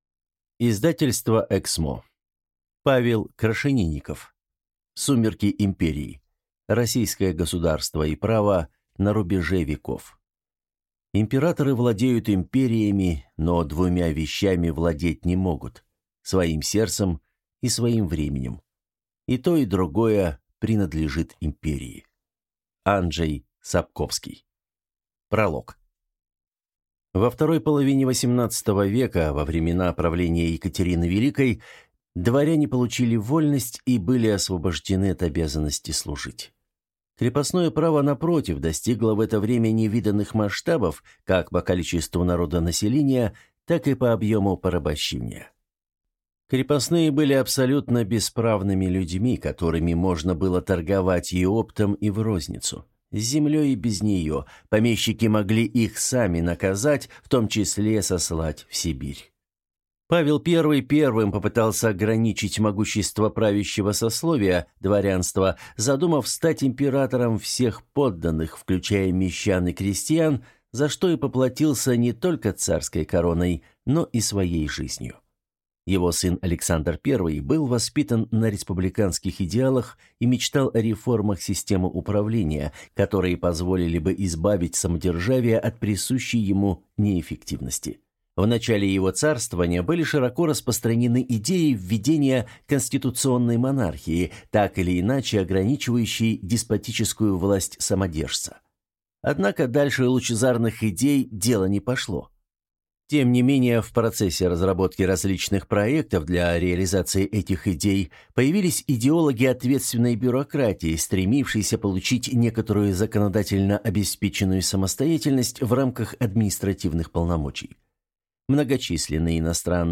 Аудиокнига Сумерки империи. Российское государство и право на рубеже веков | Библиотека аудиокниг